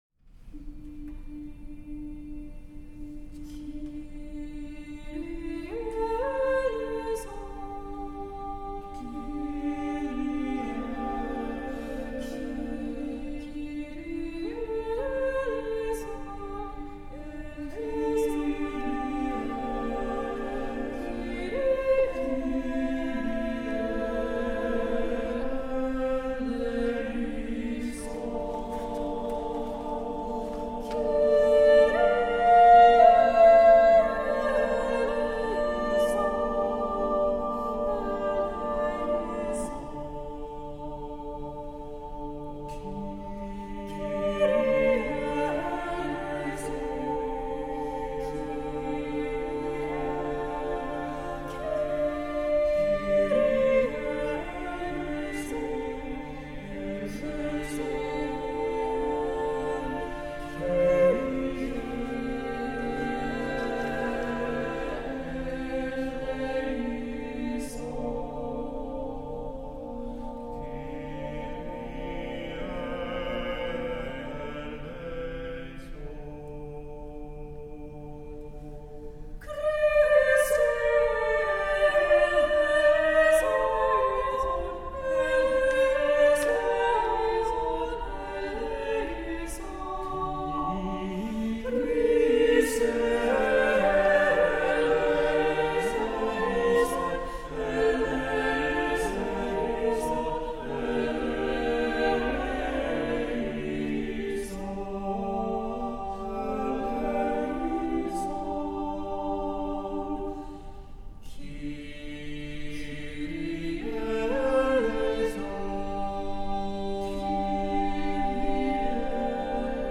Voicing: SSATBB a cappella